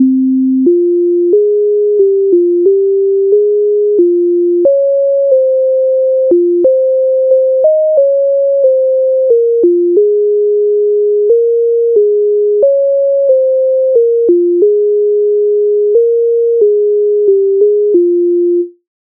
MIDI файл завантажено в тональності f-moll
Журба за журбою Українська народна пісня